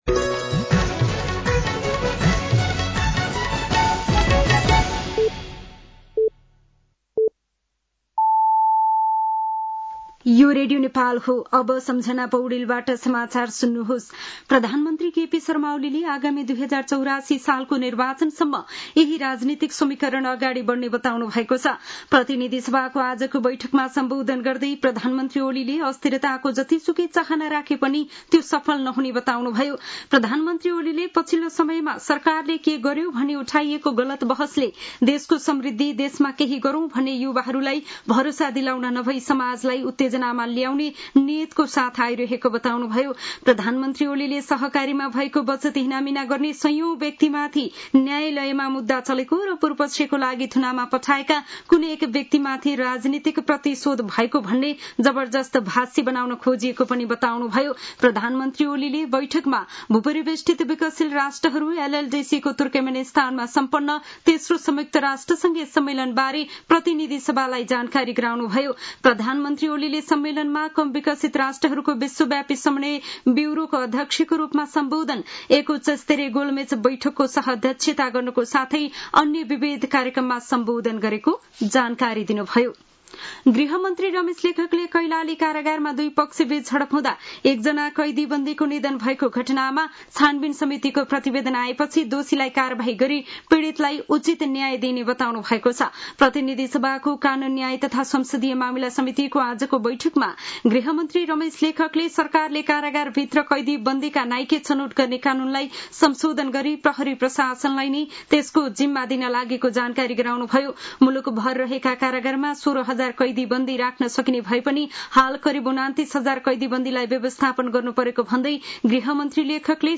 साँझ ५ बजेको नेपाली समाचार : २८ साउन , २०८२